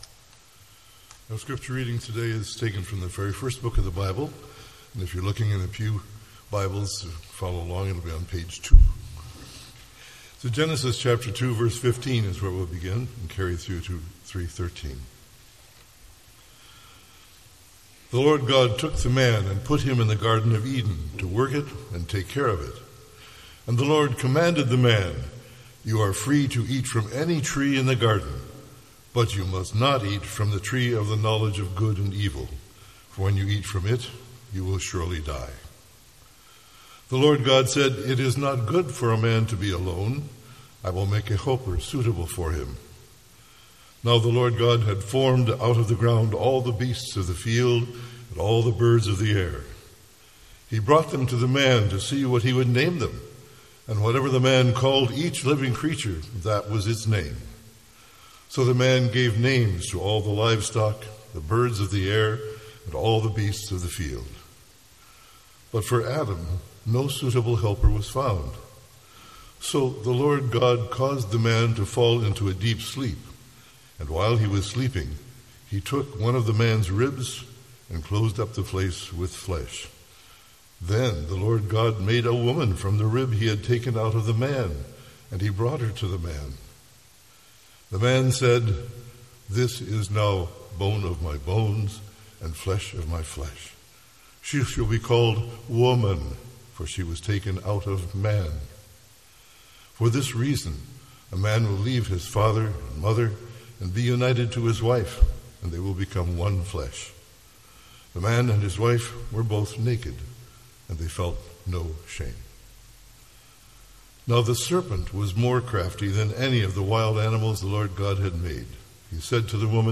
MP3 File Size: 26.4 MB Listen to Sermon: Download/Play Sermon MP3